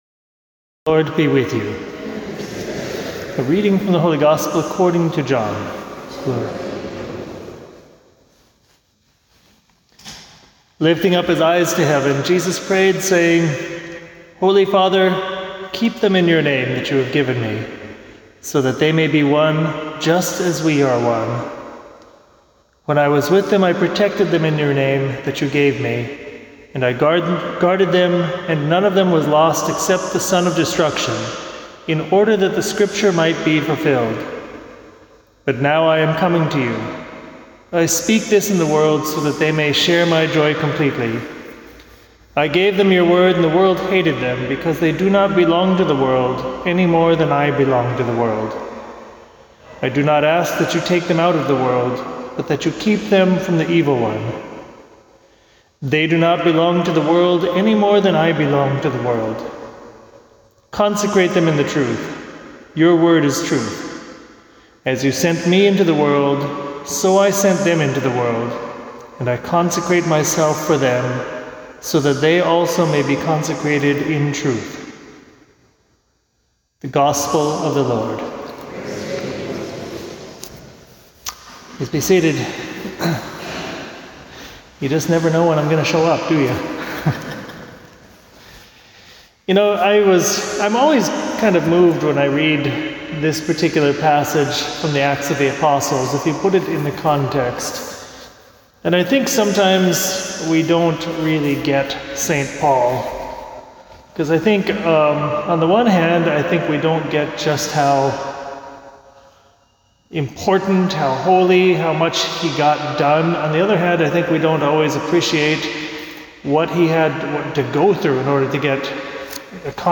at Resurrection Parish on May 15th.